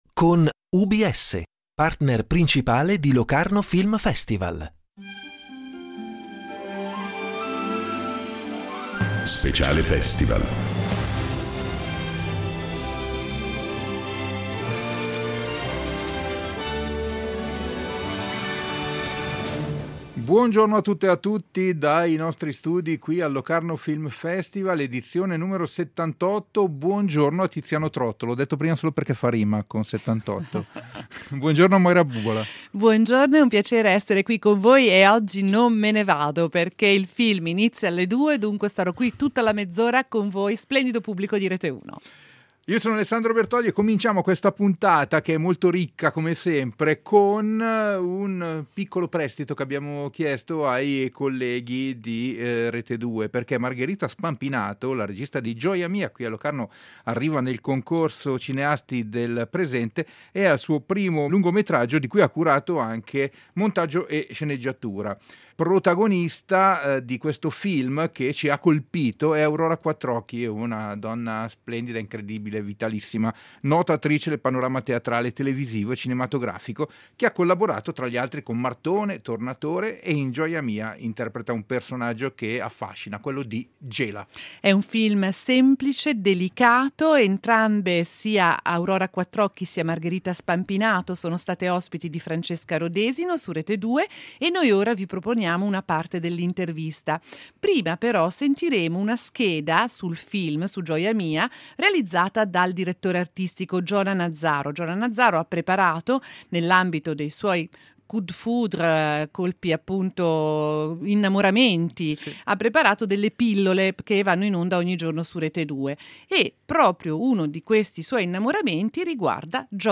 In diretta dal Locarno Film Festival